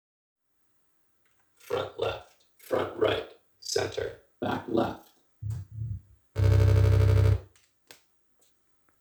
The results: Using plug it is starting to play with aplay, the sound is better but not ideal...